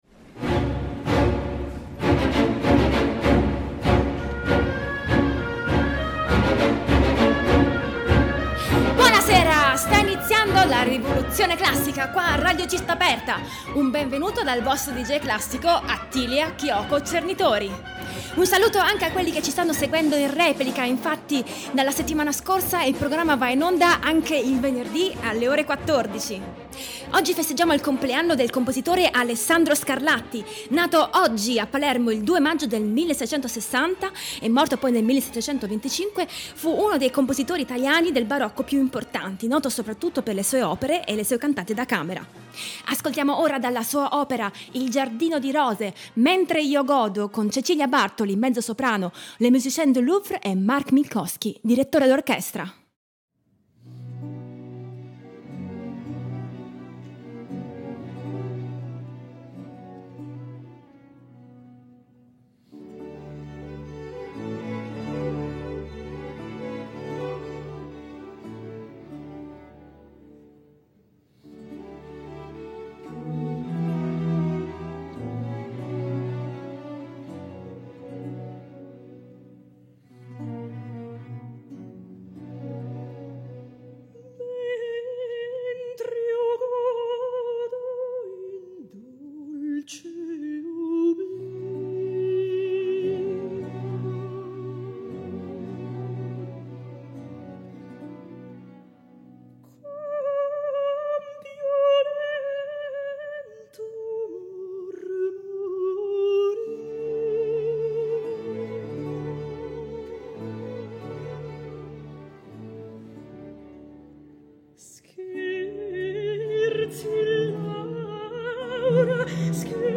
Ospite di questa puntata la clavicembalista